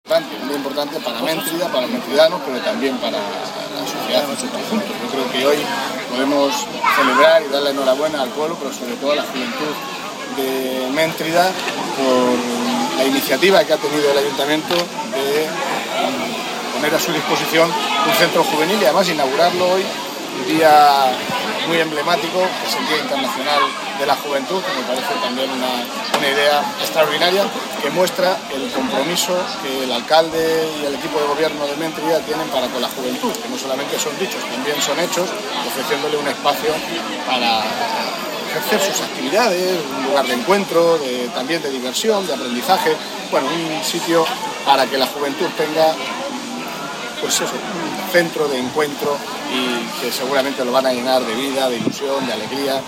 corte2_mentrida_centrojuvenil_inauguracion_delegadojuntatoledo.mp3